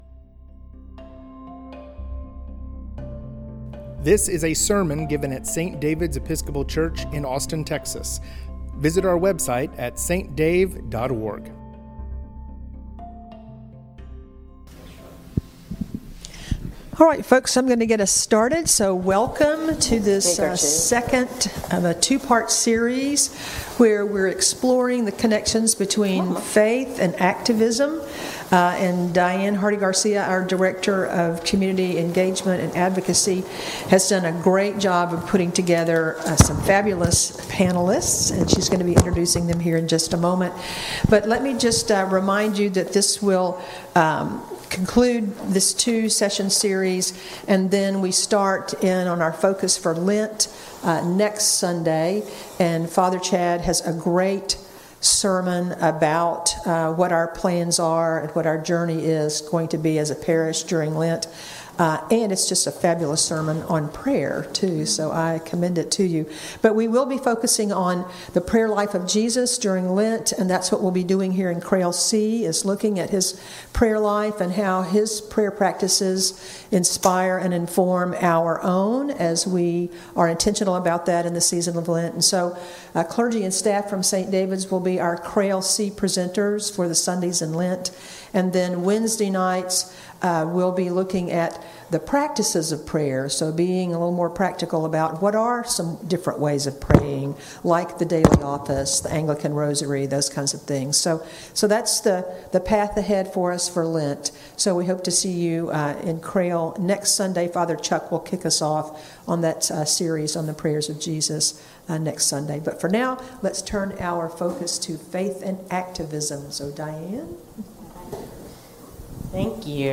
This recording was the second of a two-part panel discussing the topic of Faith and Activism.